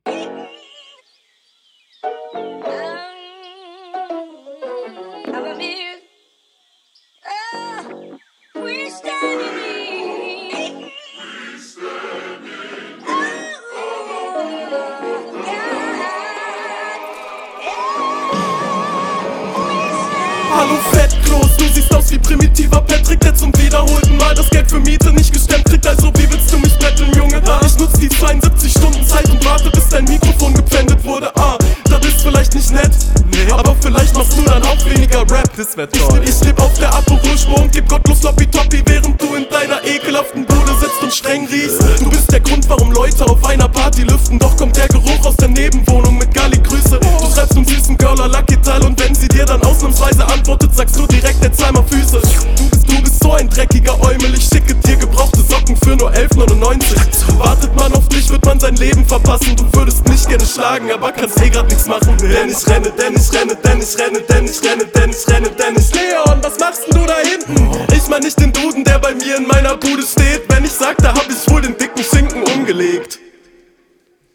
HR2: Cooler Beat.